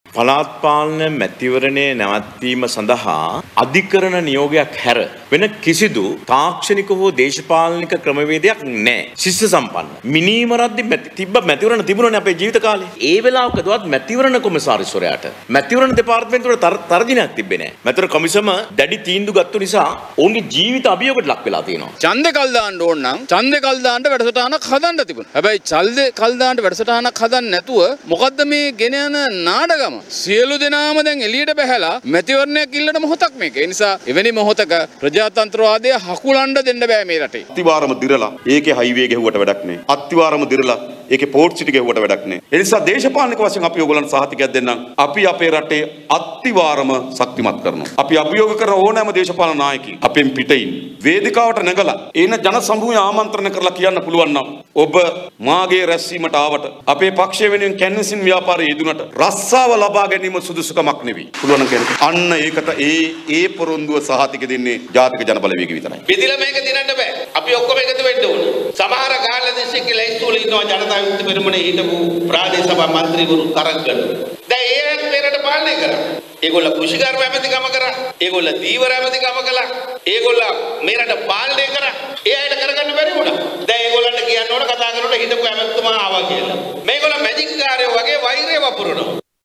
ඔහු මෙම අදහස් පළ කළේ අද අම්පාර ප්‍රදේශයේ පැවති ජන හමුවකට එක් වෙමින්.